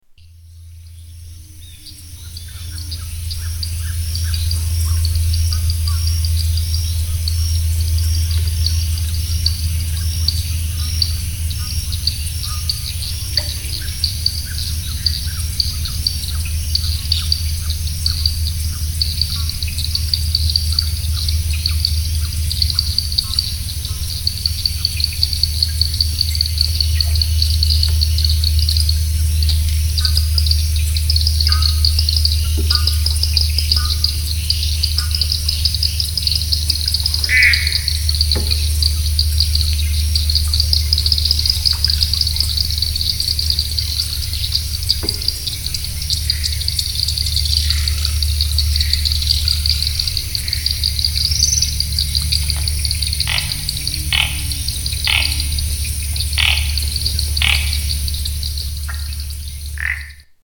Soundscape of birds, frogs and insects at Monte Al